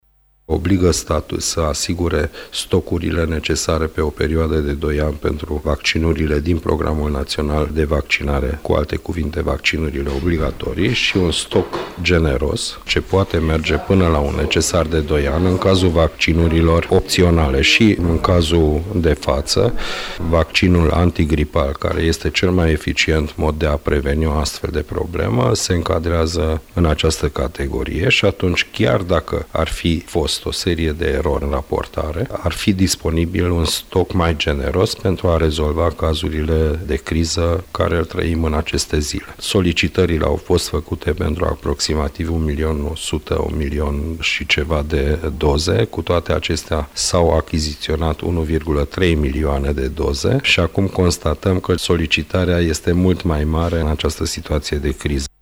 Ce prevede această lege, ne spune președintele Comisie de Sănătate din Senat, Laszslo Attila